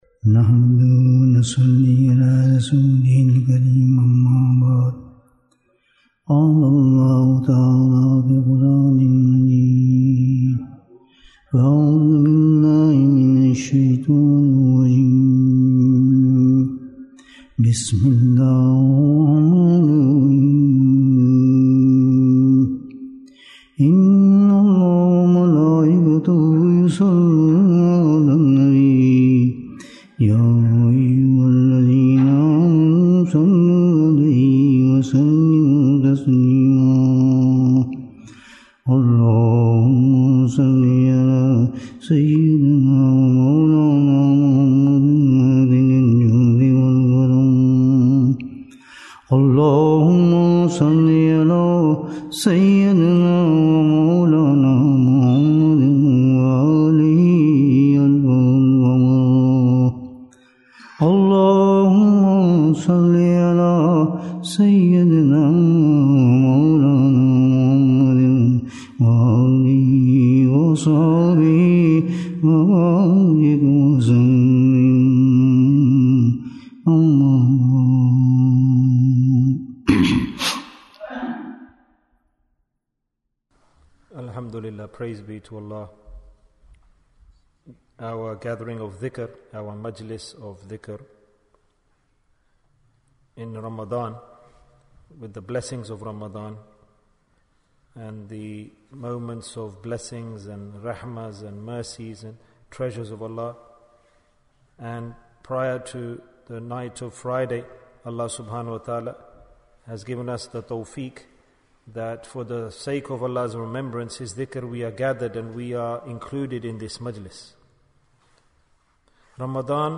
Who is Muttaqi? Bayan, 66 minutes13th April, 2023